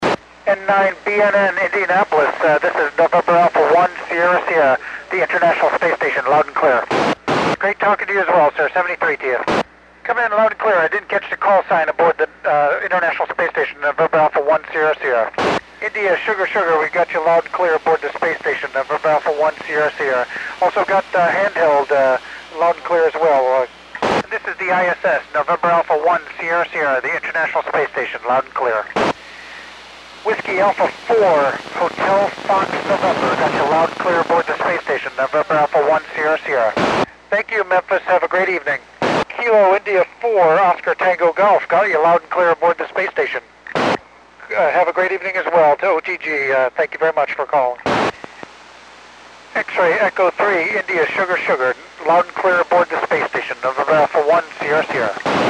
When I record, I leave the squelch off, and when I edited the recording, I deleted most of the squelch noise to reduce the size of the recording, so the "squelch tail" separates transmissions by Col. Wheelock.